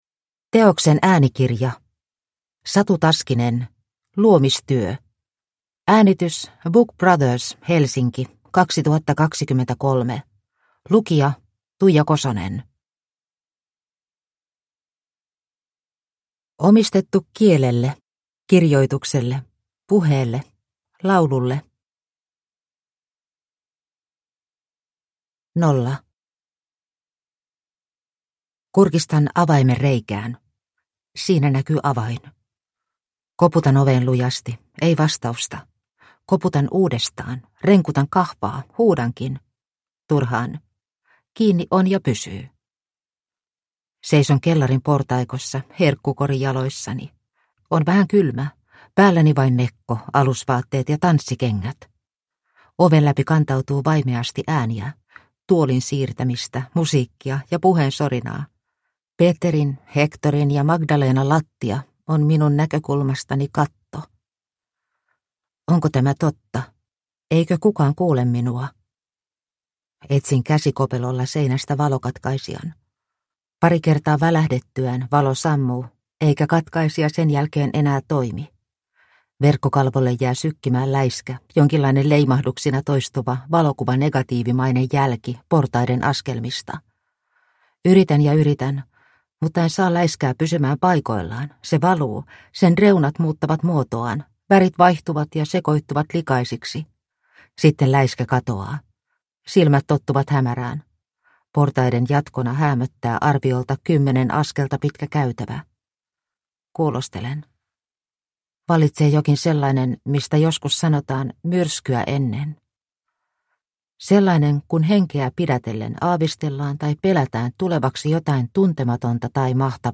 Luomistyö – Ljudbok – Laddas ner